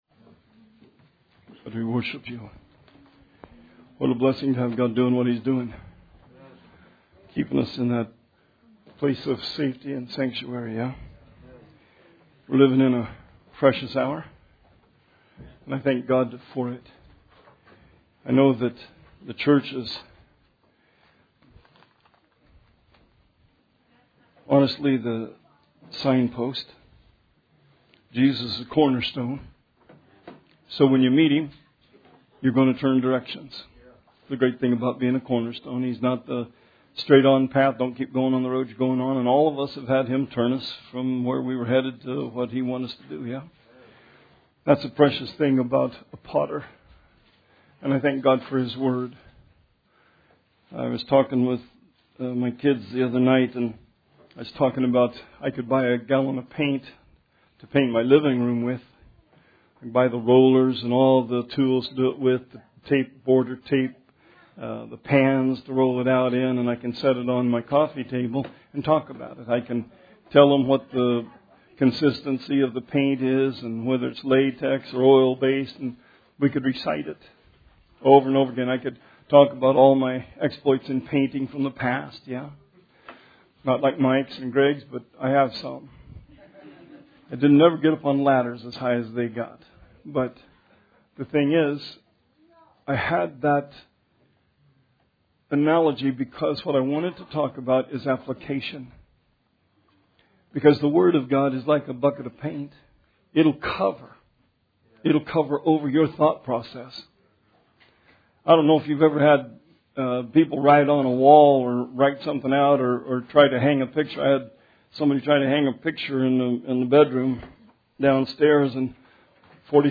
Sermon 9/13/20